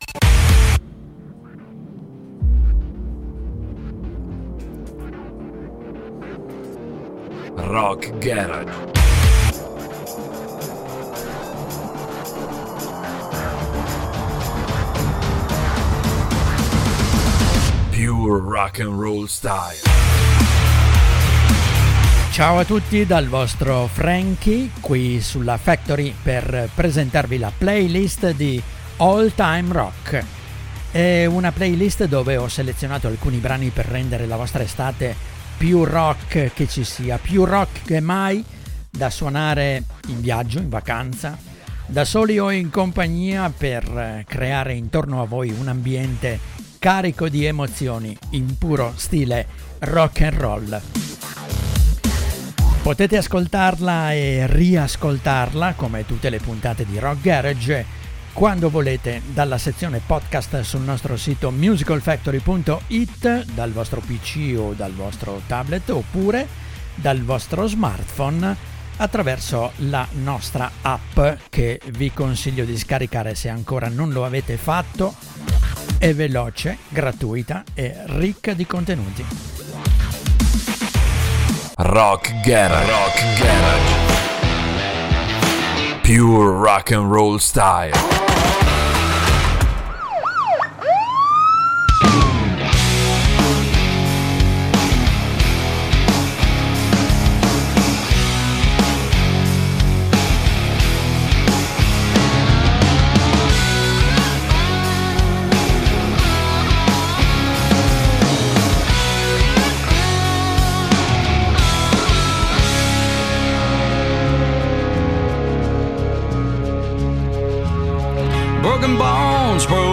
alcuni dei più bei classici del rock
un ambiente carico di emozioni
in puro stile rock’n’roll